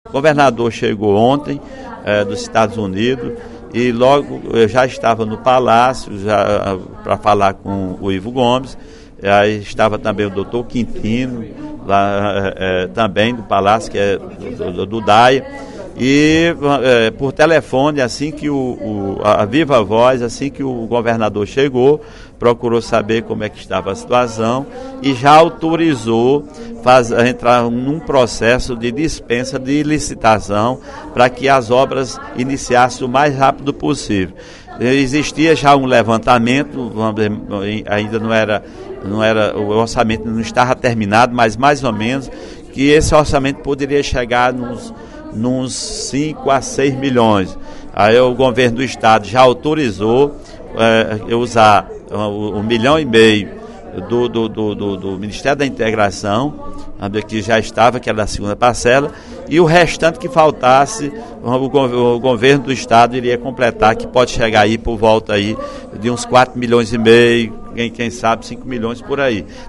O deputado Sineval Roque (PSB) disse nesta quinta-feira (08/03), em pronunciamento na tribuna da Assembleia Legislativa, que esteve ontem com o governador do Estado, Cid Gomes, e que ele anunciou o início da reconstrução do canal do rio Granjeiro, no Crato.